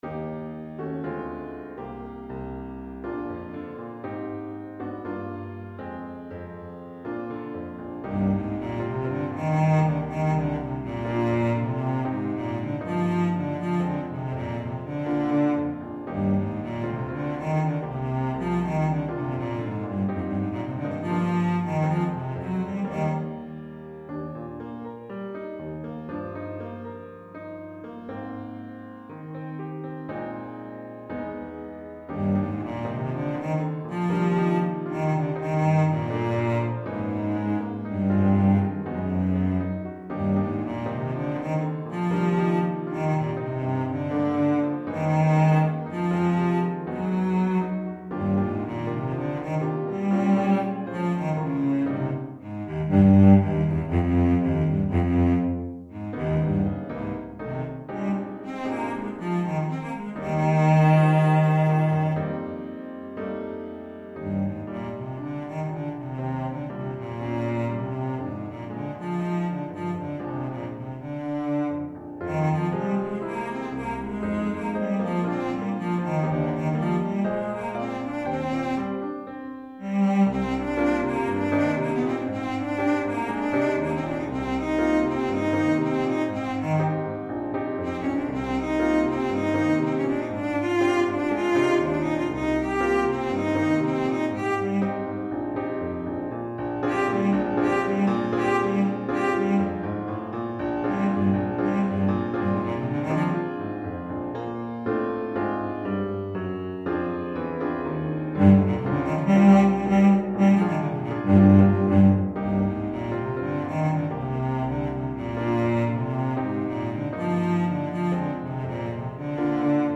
Violoncelle et Piano